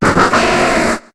Cri de Massko dans Pokémon HOME.